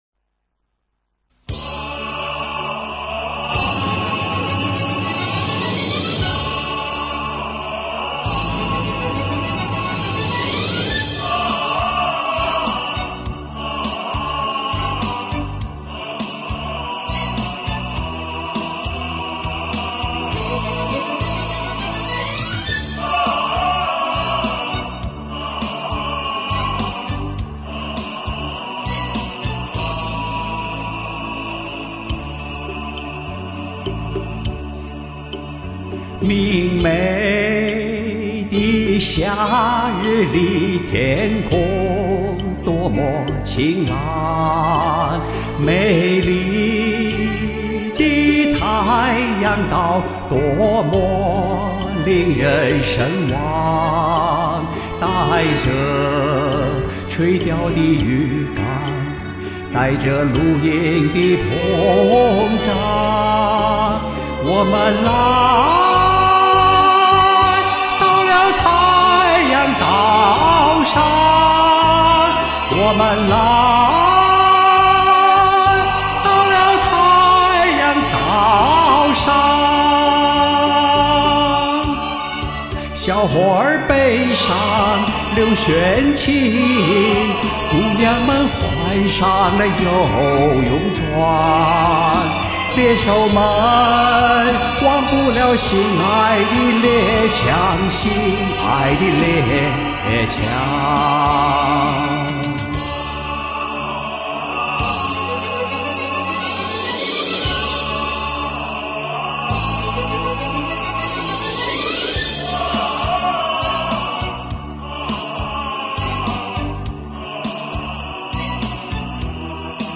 好唱，美丽欢快的两首歌。